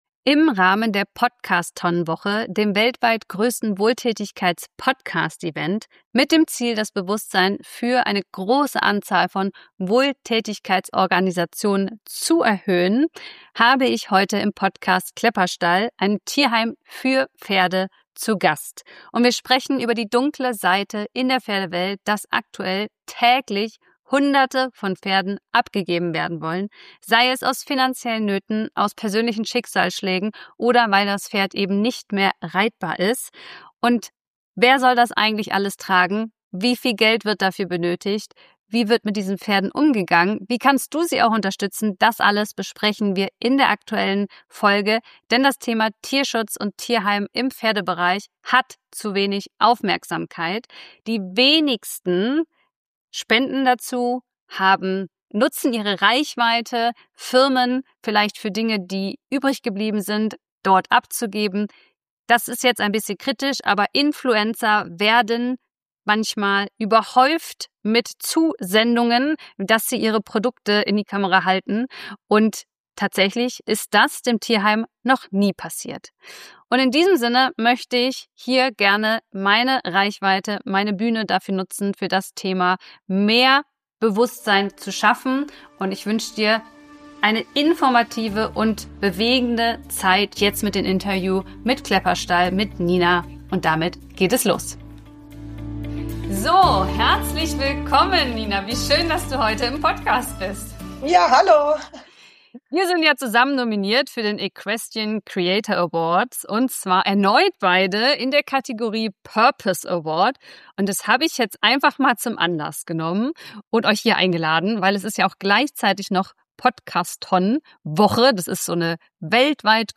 Im Rahmen der Podcasthon Woche, dem weltweit größten Wohltätigkeits-Podcast-Event mit dem Ziel das Bewusstsein für eine große Anzahl von Wohltätigkeitsorganisationen weltweit zu erhöhen ist dieses bewegende Interview mit Klepperstall e.V. entstanden.